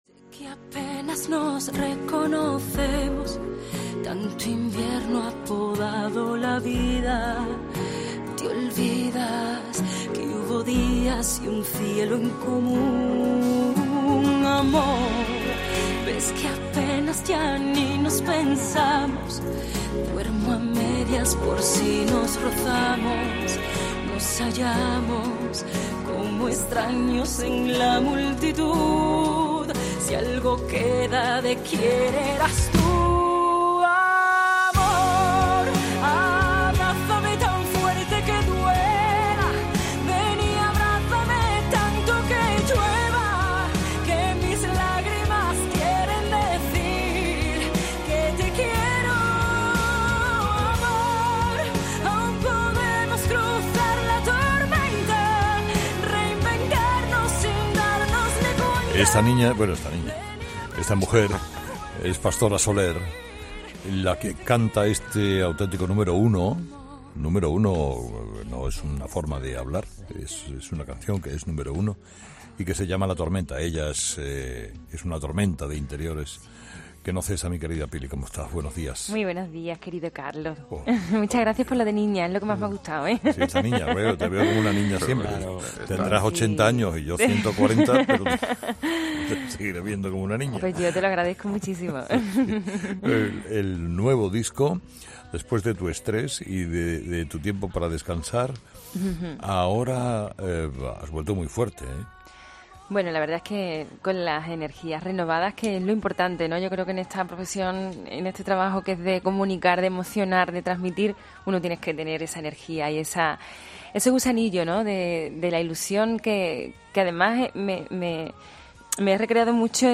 Cali y el Dandee con Carlos Herrera en el estudio de la Cadena COPE.